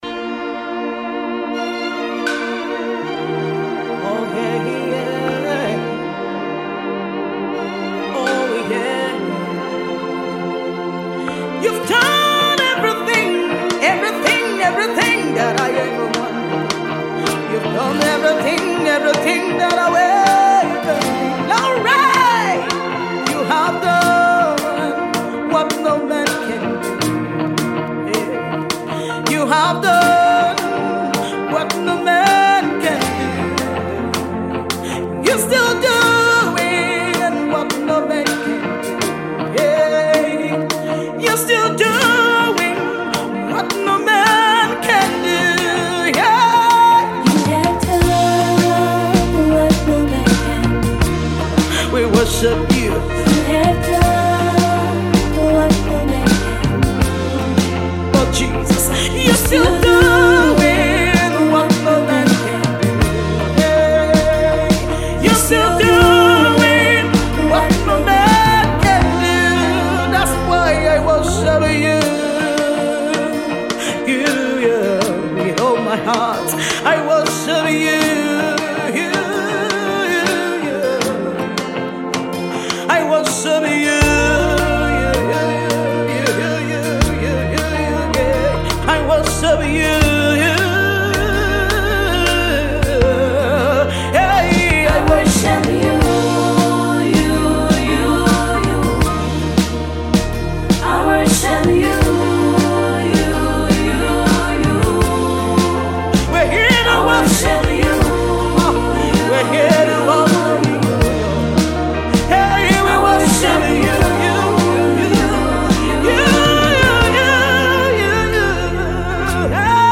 Fast rising  Gospel music artiste